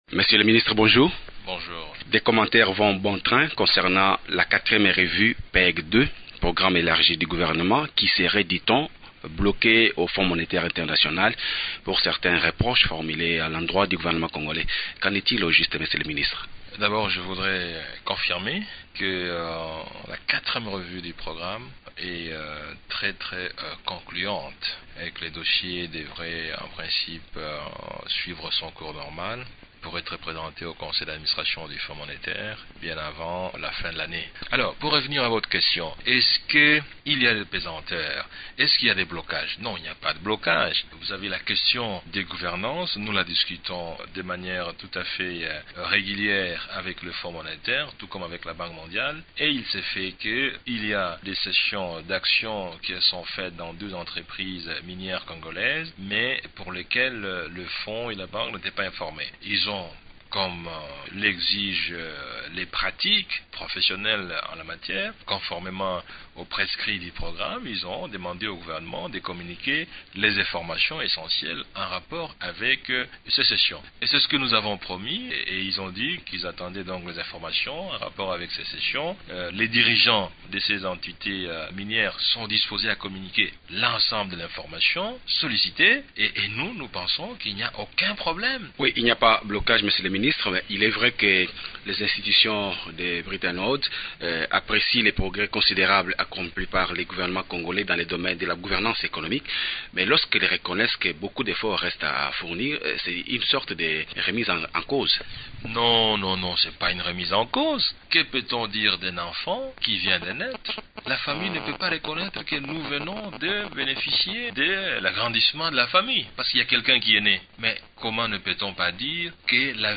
Matata Ponyo est interrogé par